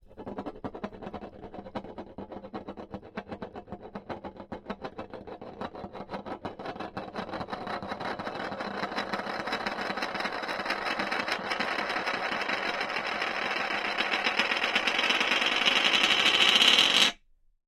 Eulerian,spinning,crescendo,glasssurface,turnedwoodenfigure,glazed,bright,slightlyrough,tight,1.ogg